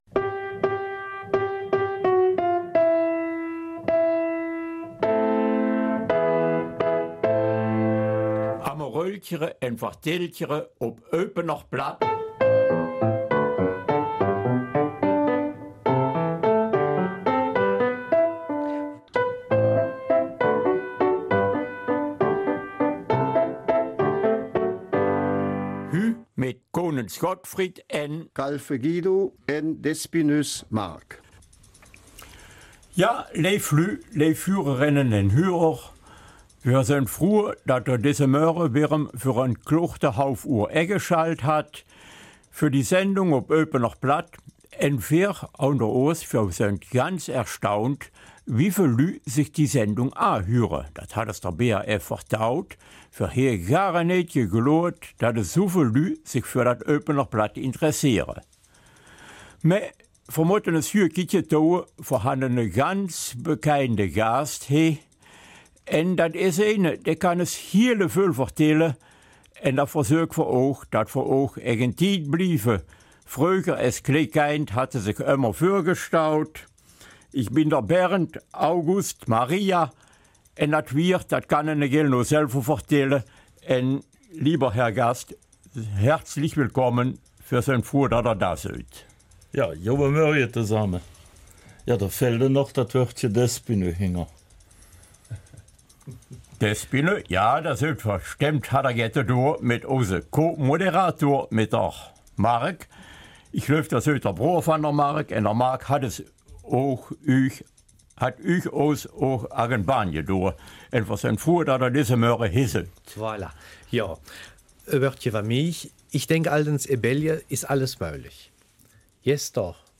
Der heutige Frühpensionär ist noch lange nicht im ''Ruhestand'', wie er in lockerer Runde erzählt.
Eupener Mundart